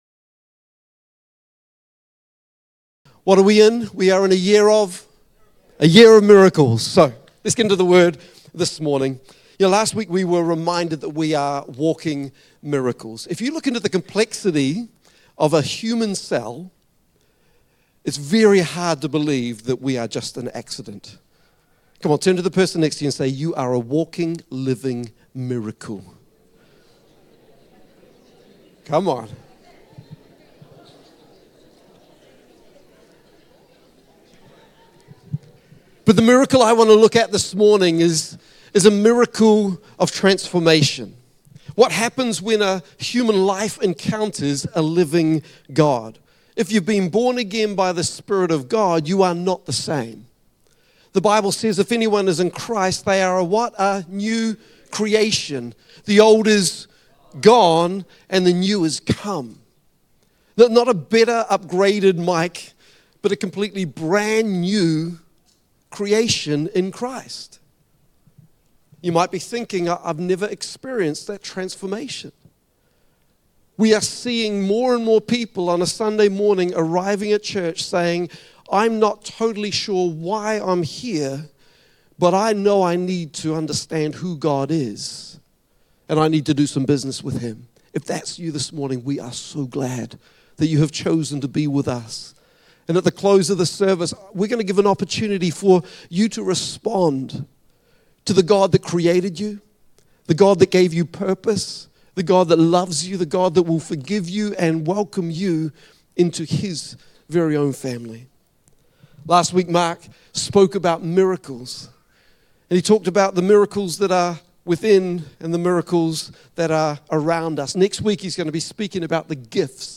Rediscover Church Exeter | Sunday Messages